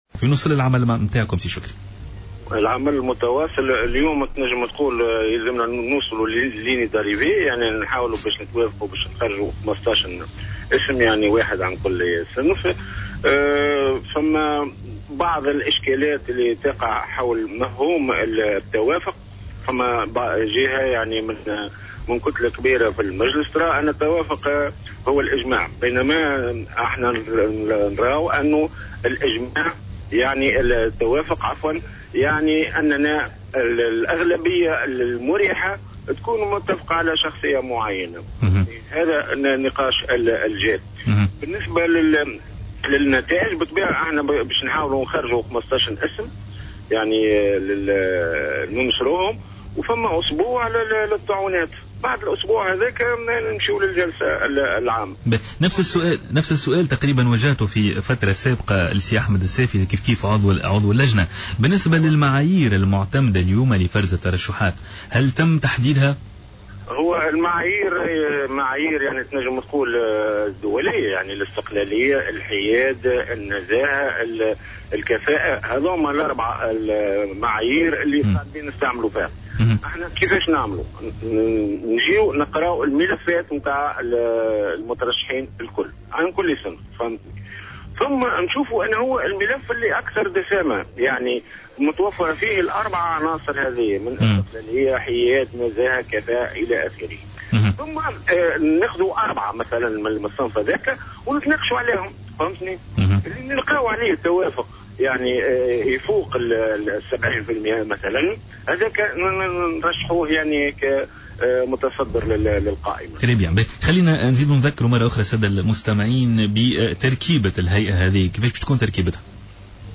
Le député Alliance démocratique et membre de la commission de tri des candidatures pour l'instance de la vérité et de la dignité, Chokri Kastli, a indiqué, dans une interview accordée à Jawhara Fm que la composition de l'Instance sera probablement annoncée ce mercredi lors d'une plénière.